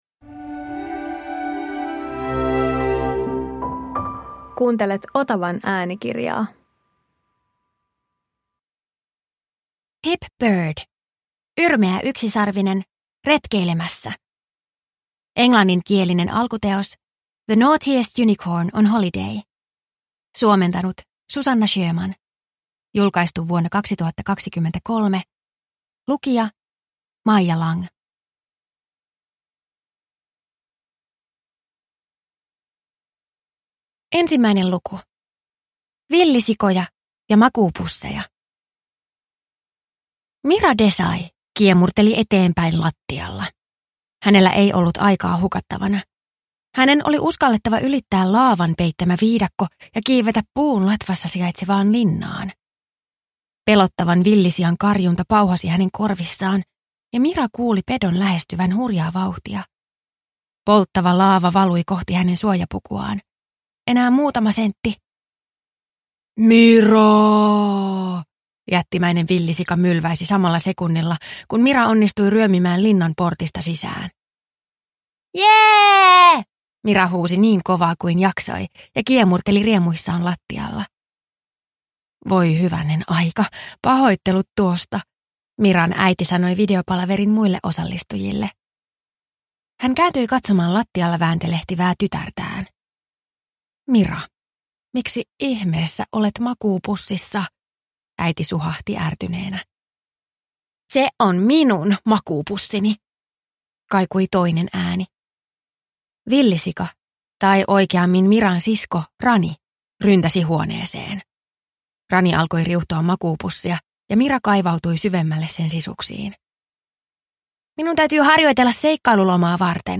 Yrmeä yksisarvinen retkeilemässä – Ljudbok